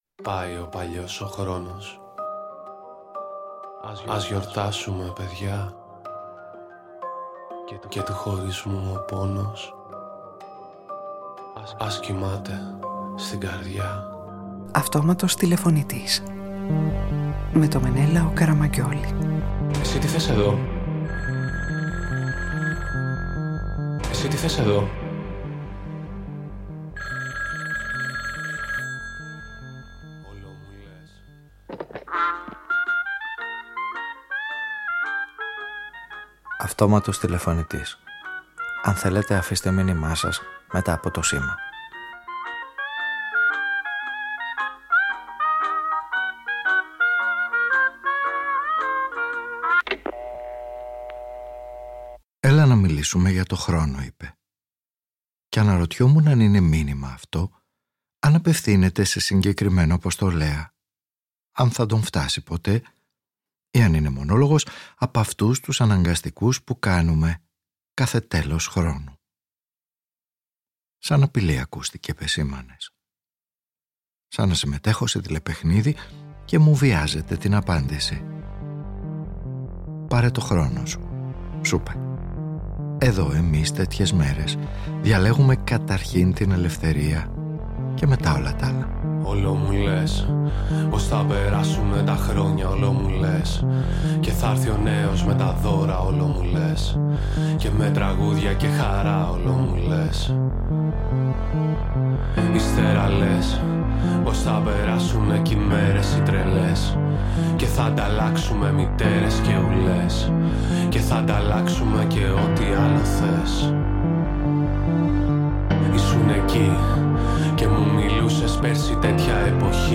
Ο ήρωας της σημερινής ραδιοφωνικής ταινίας ψάχνει τρόπους να επαναπροσδιορίσει τη σχέση του με το χρόνο κάνοντας το παρόν του μια διαρκή περιήγηση σε όσα τον εμπνέουν και σε όσα τον παρηγορούν.